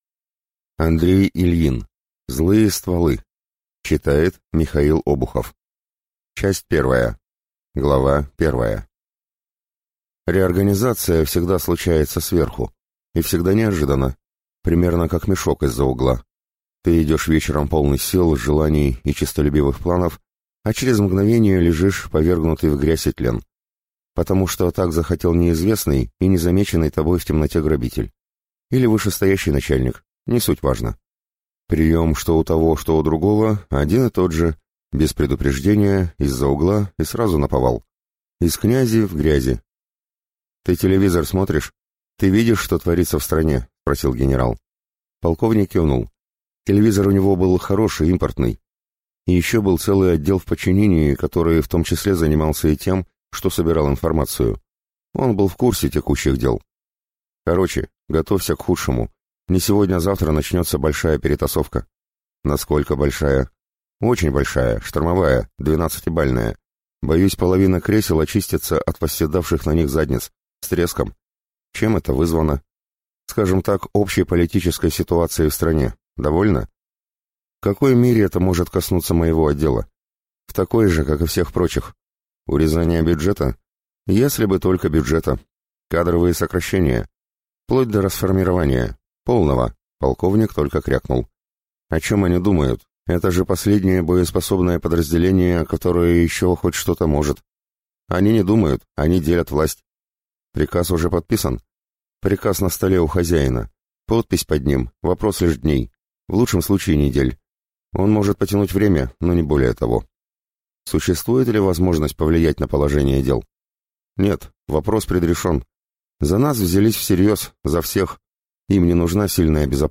Аудиокнига Злые стволы | Библиотека аудиокниг